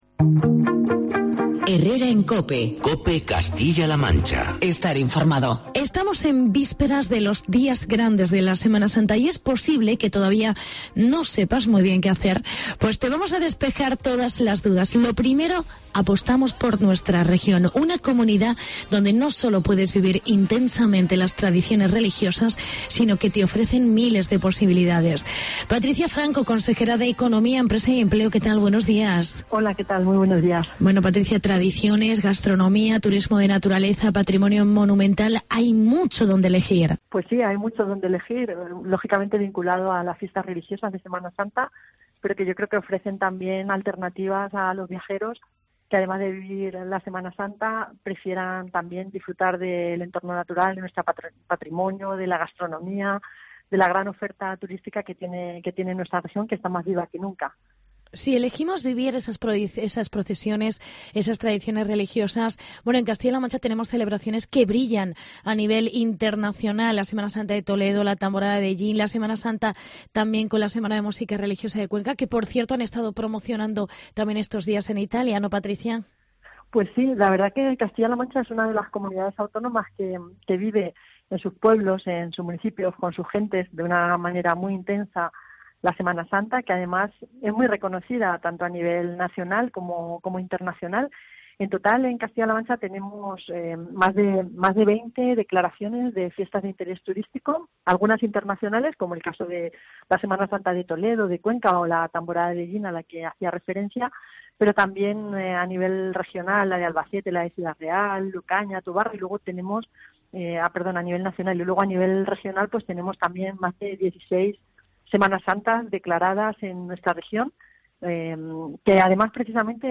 Entrevista con la Consejera de Economía: Patricia Franco